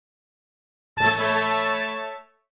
tada.mp3